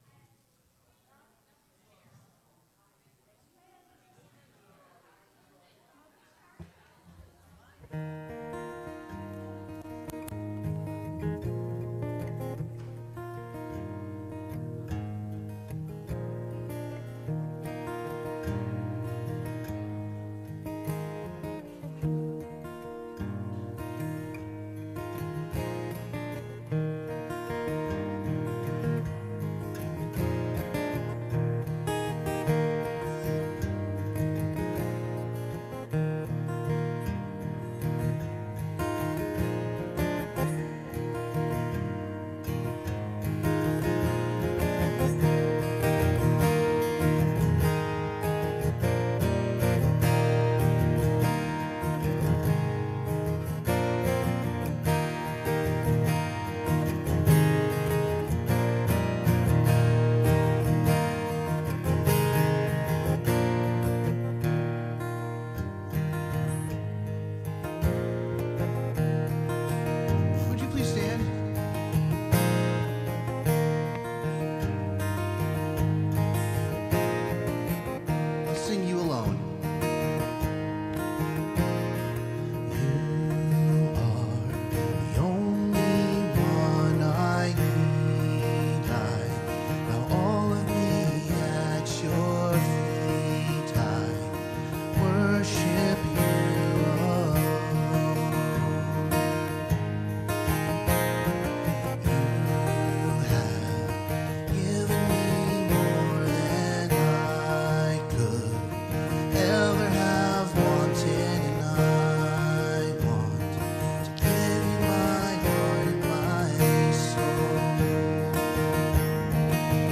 CCC Sermons Passage